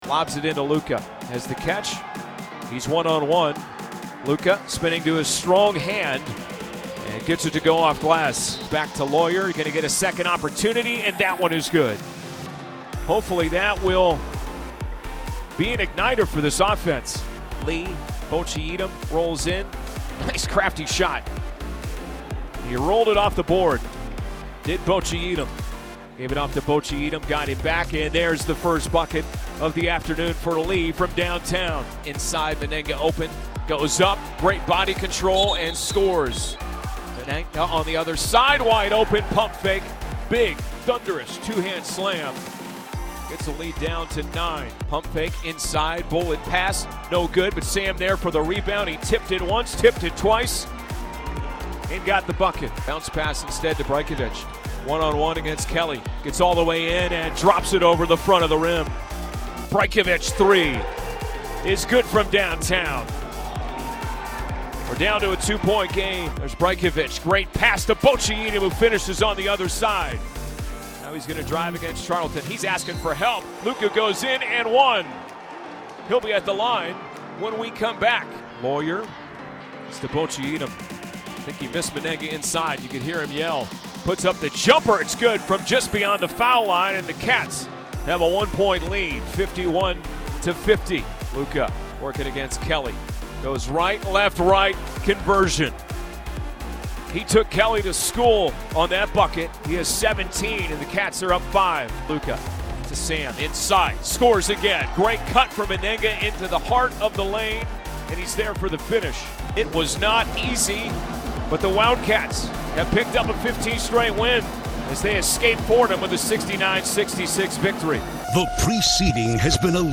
Radio Highlights